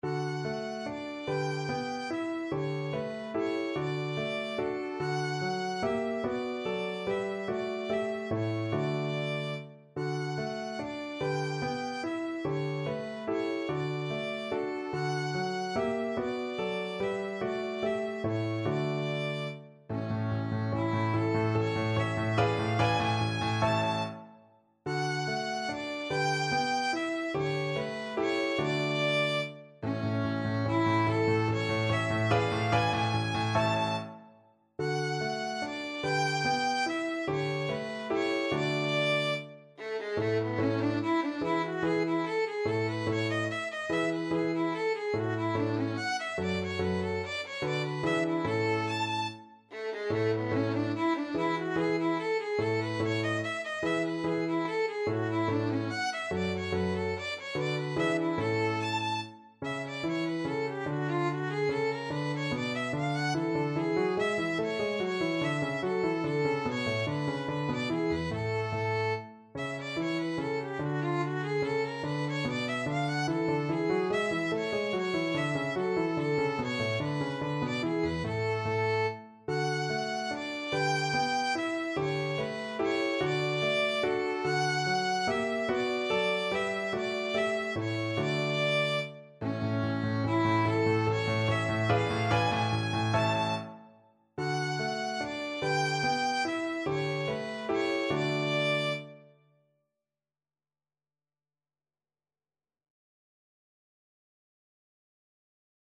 Violin
D major (Sounding Pitch) (View more D major Music for Violin )
3/4 (View more 3/4 Music)
Andantino = c.145 (View more music marked Andantino)
Classical (View more Classical Violin Music)